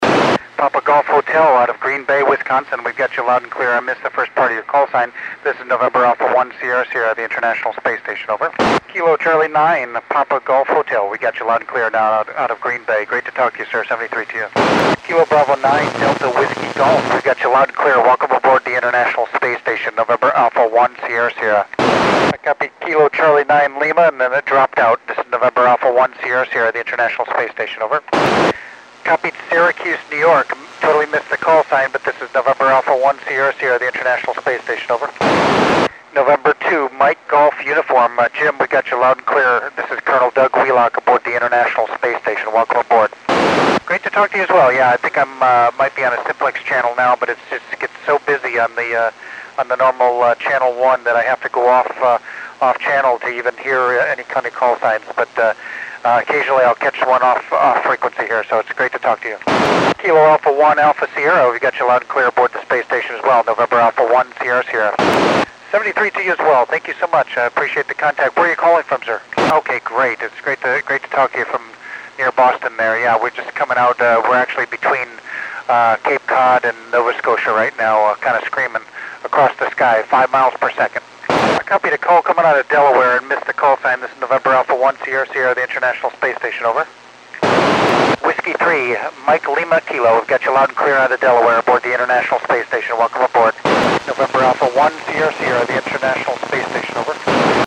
Col. Doug Wheelock (NA1SS) works U.S. stations on 06 November 2010 at 1912 UTC.
Col. Doug Wheelock (NA1SS) wks U.S. stations